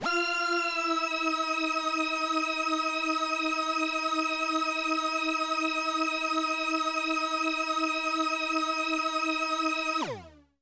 小提琴单音 " 小提琴 Csharp6
描述：在巴塞罗那Universitat Pompeu Fabra音乐技术集团的goodsounds.org项目的背景下录制。
Tag: 好声音 单音符 小提琴 多重采样 纽曼-U87 Csharp6